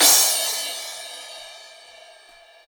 Closed Hats
07_Perc_01_SP.wav